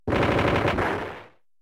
MiningCollapse.mp3